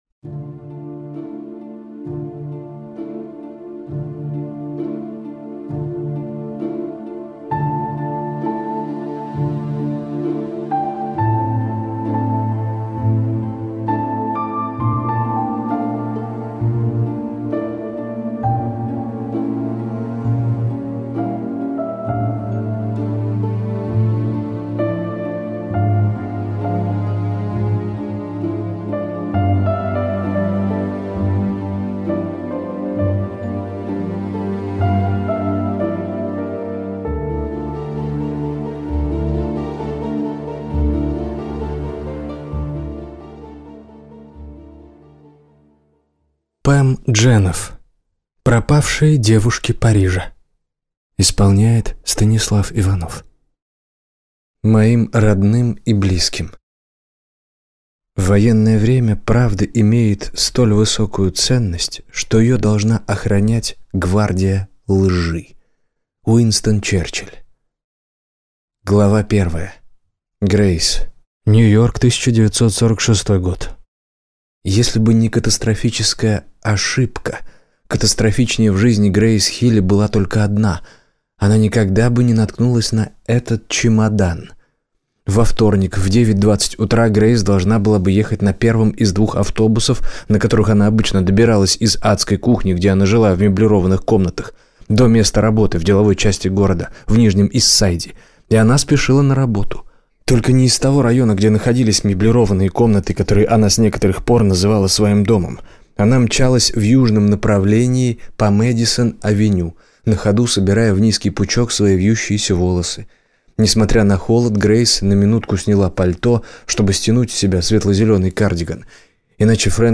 Аудиокнига Пропавшие девушки Парижа | Библиотека аудиокниг